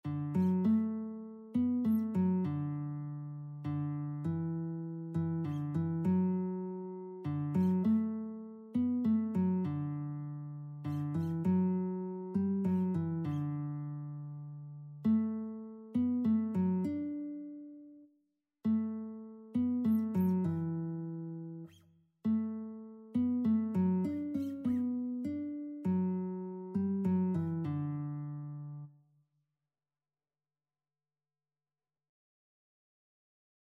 Christian
3/4 (View more 3/4 Music)
Classical (View more Classical Lead Sheets Music)